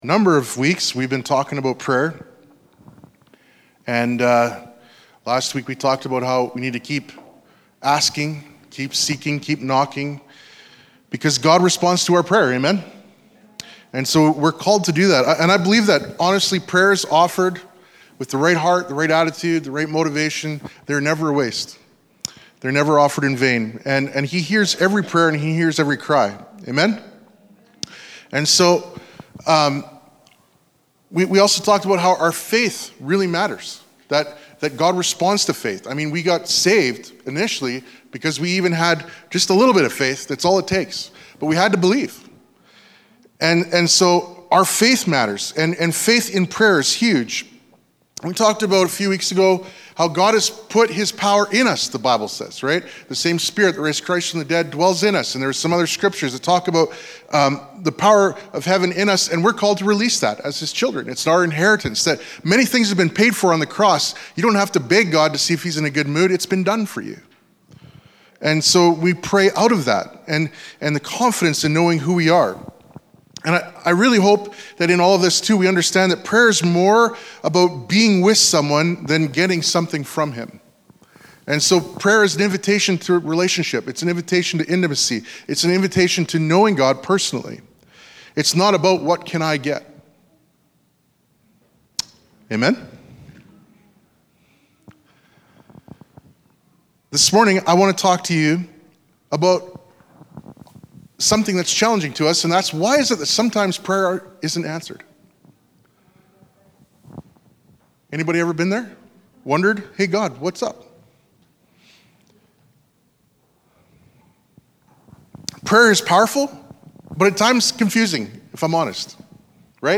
Sermons | Family Church in Maple Creek